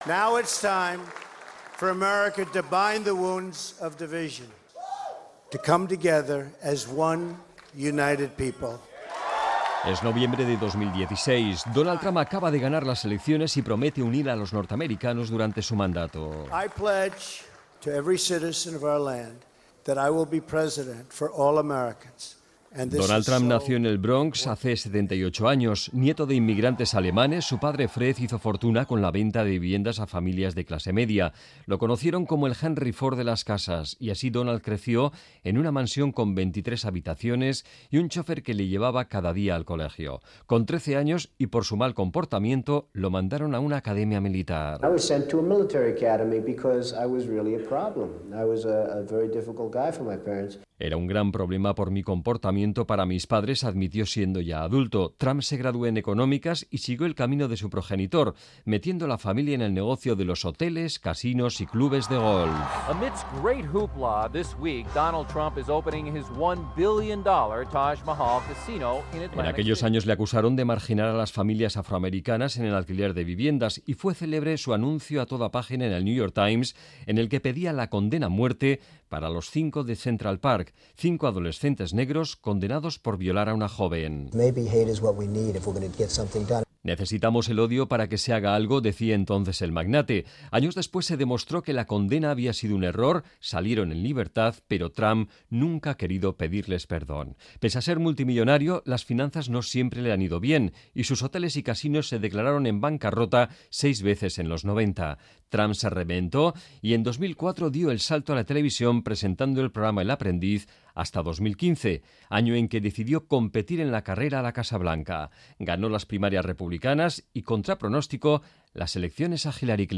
Reportaje biográfico sobre el siempre polémico Donald Trump que, a sus 78 años, pelea por regresar a la Casa Blanca. Un segundo intento de asalto con pocos precedentes en la historia.